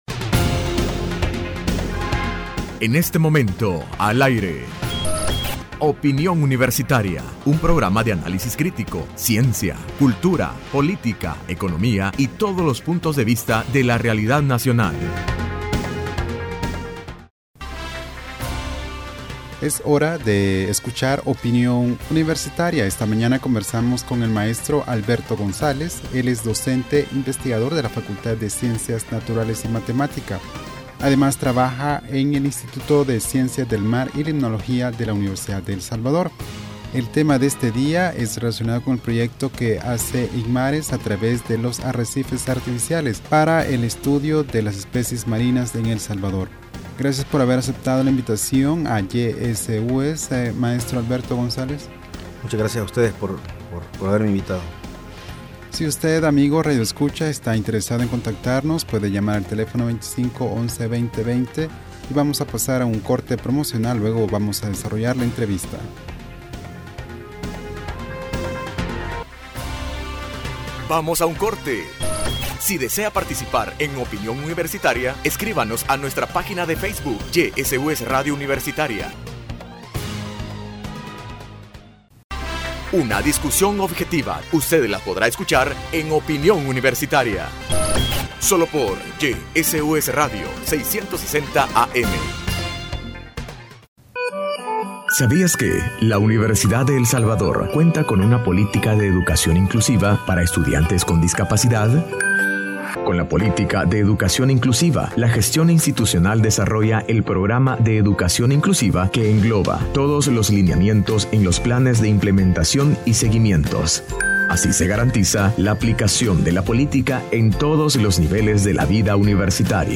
Entrevista Opinión Universitaria(15 de Julio 2015): Arrecifes Artificiales en las playas de El Salvador.